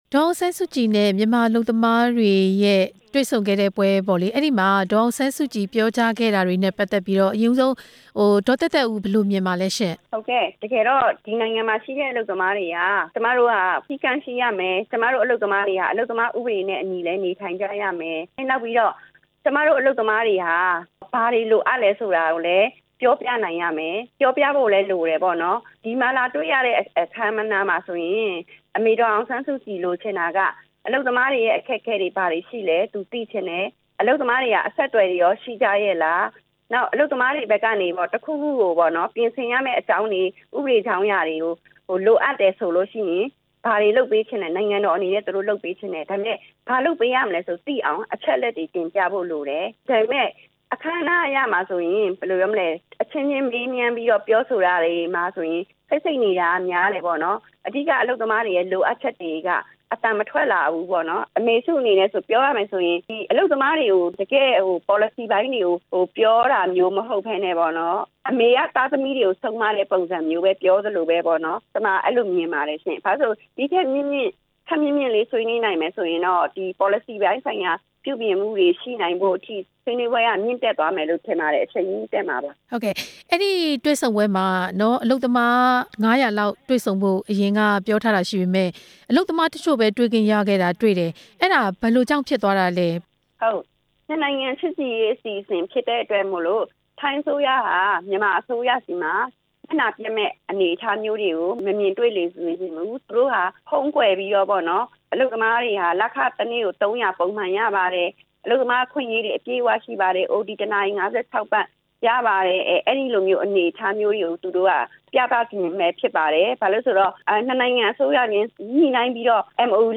အလုပ်သမားအရေးလှုပ်ရှားသူ
ဆက်သွယ်မေးမြန်းထားတာ